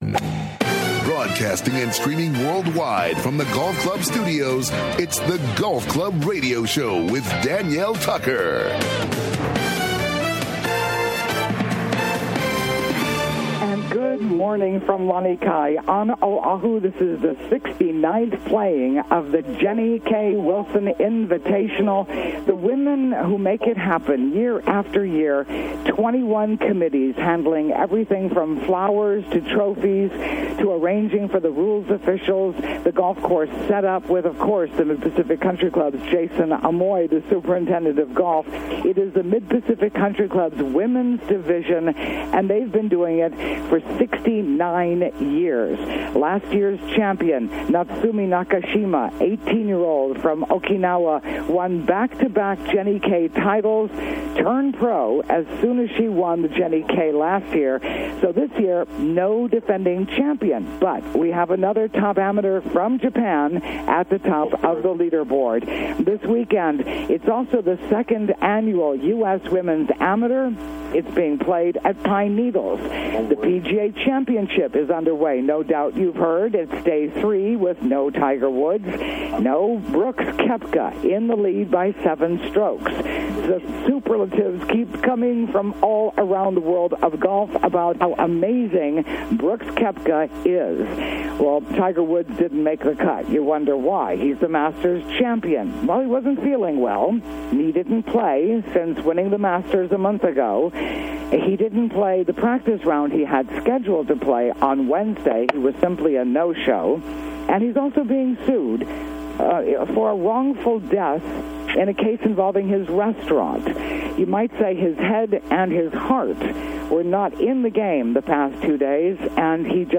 Live SATURDAY MORNINGS: 7:00 AM - 8:30 AM HST